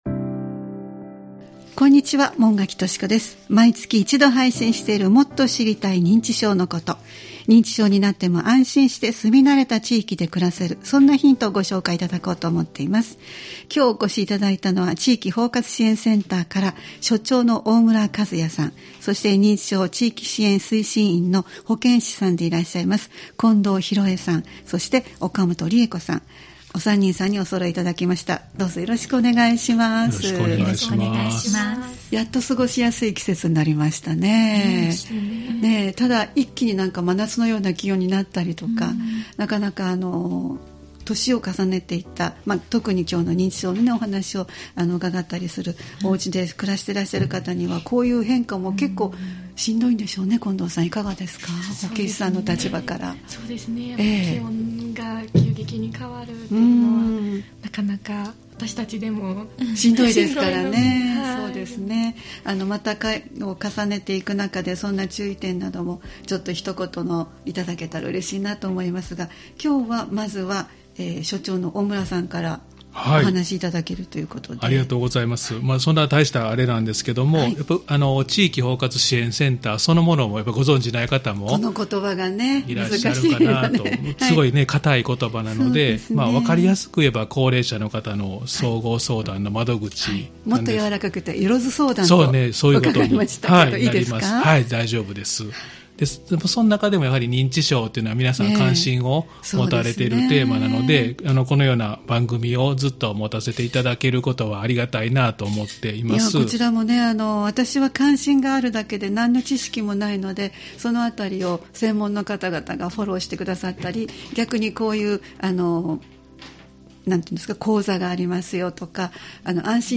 毎月第1月曜日に配信するポッドキャスト番組「もっと知りたい認知症のこと」 スタジオに専門の方をお迎えして、認知症に関連した情報、認知症予防の情報、介護や福祉サービスなどを紹介していただきます（再生ボタン▶を押すと番組が始まります）